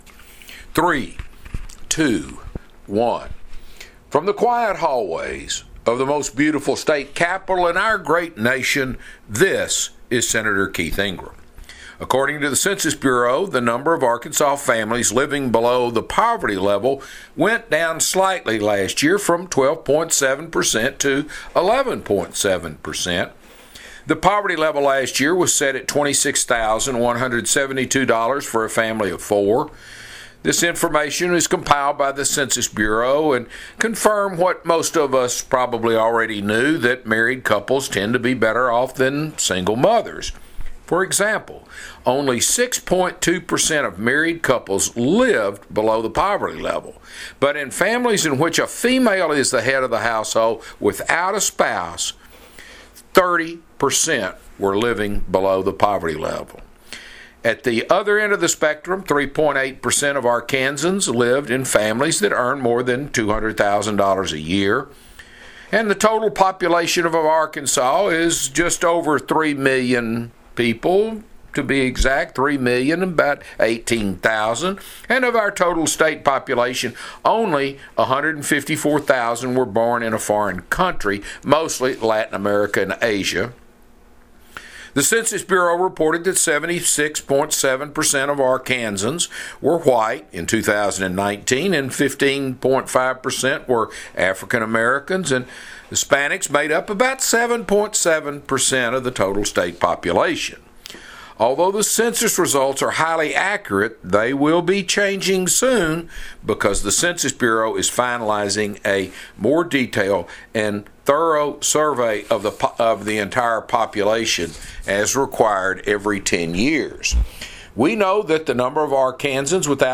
Weekly Address – September 25, 2020 | 2020-09-24T22:23:46.223Z | Sen. Keith Ingram | 2020-09-24T22:23:46.223Z | Sen.